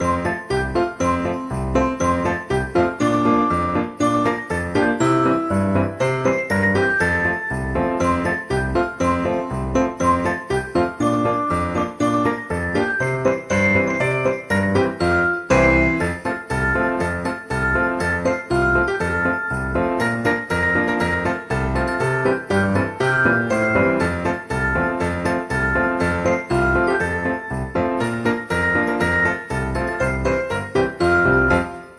christmas-music.mp3